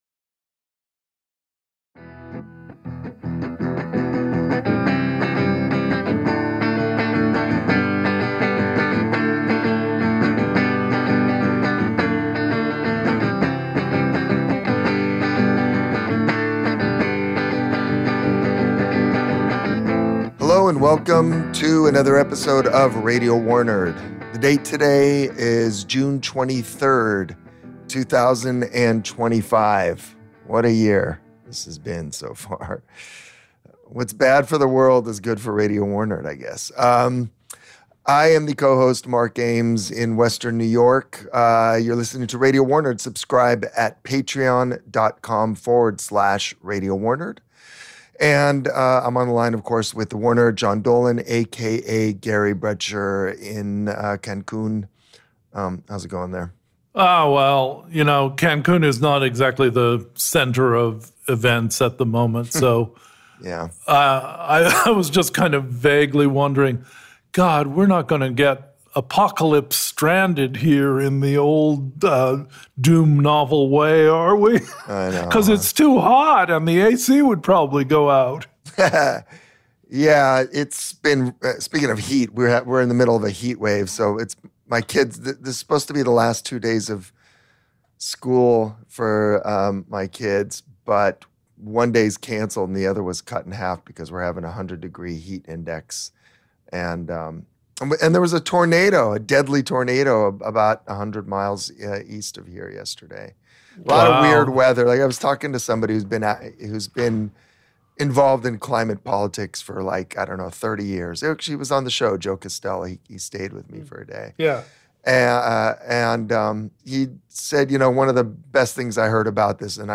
Music interlude